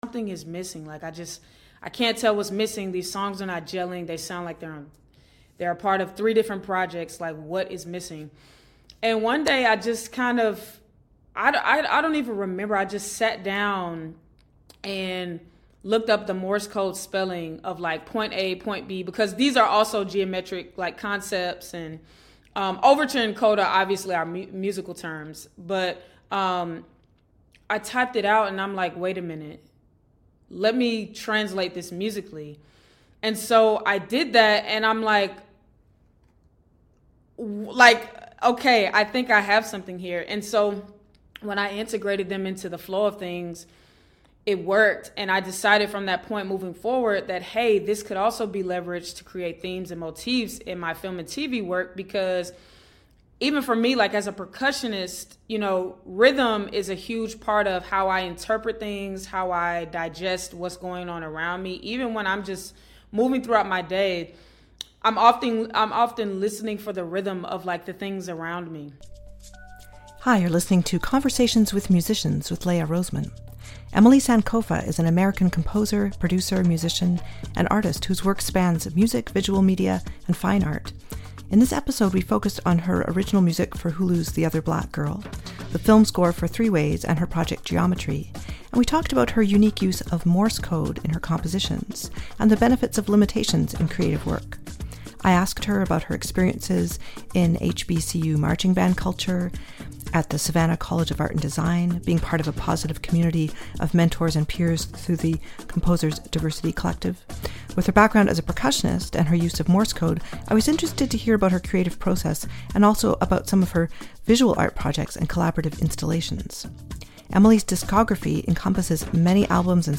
Conversations with Musicians